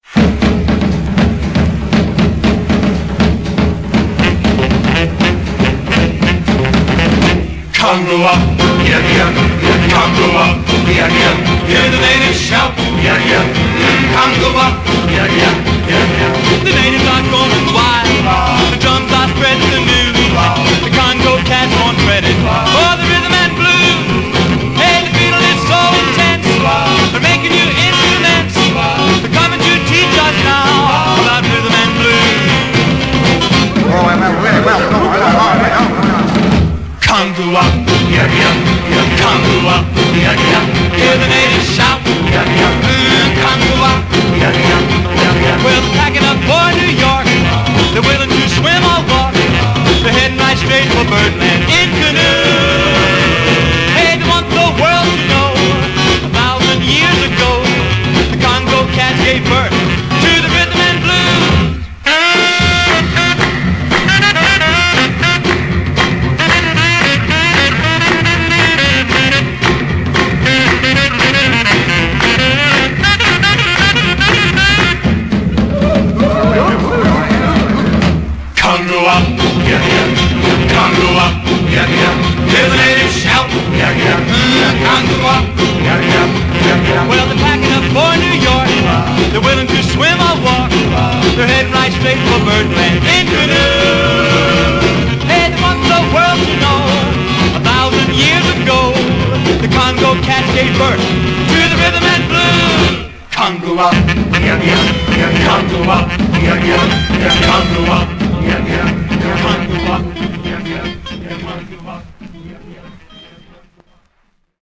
Псевдостерео